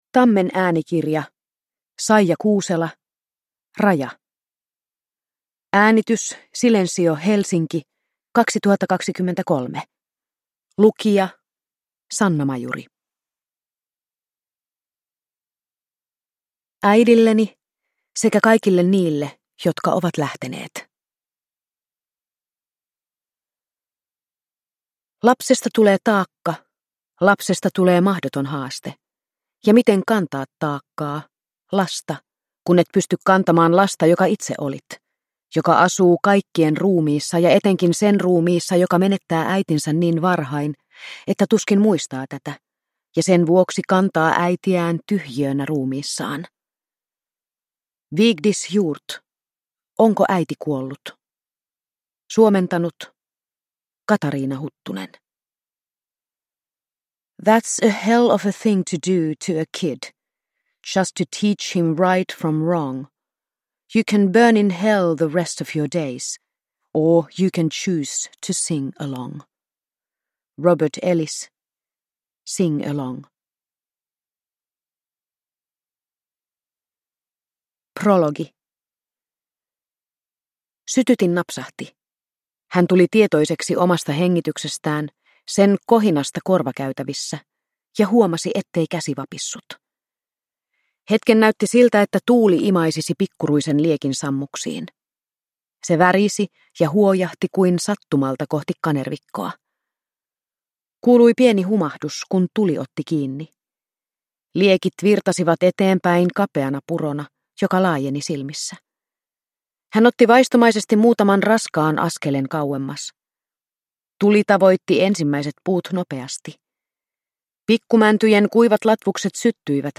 Raja – Ljudbok – Laddas ner